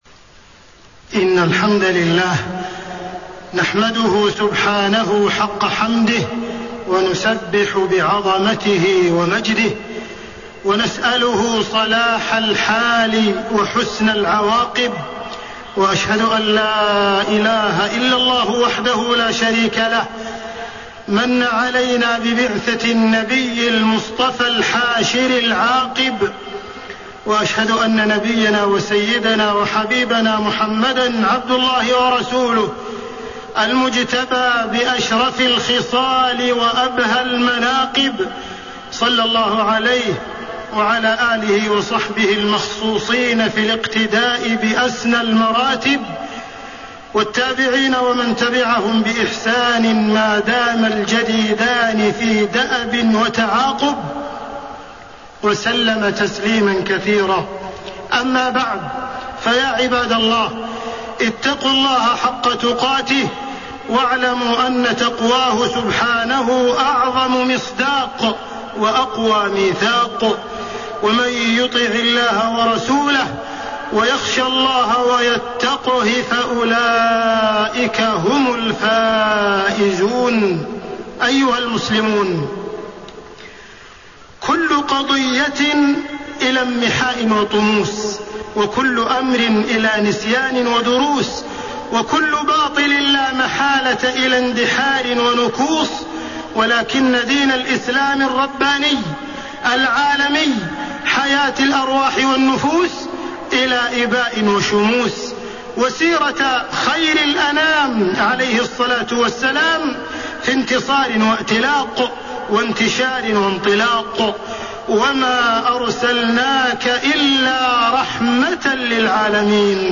تاريخ النشر ١٣ ربيع الأول ١٤٣٤ هـ المكان: المسجد الحرام الشيخ: معالي الشيخ أ.د. عبدالرحمن بن عبدالعزيز السديس معالي الشيخ أ.د. عبدالرحمن بن عبدالعزيز السديس السيرة النبوية ووجوب تعلمها